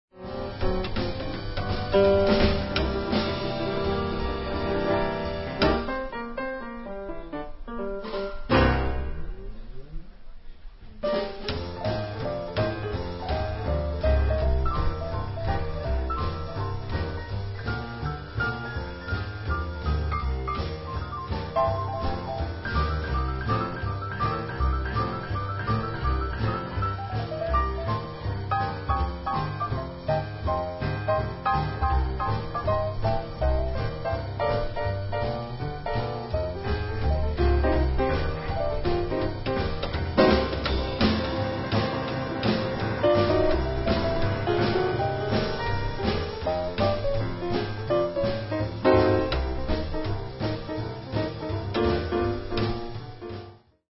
cornetta
trombone
clarinetto
pianoforte
contrabbasso
batteria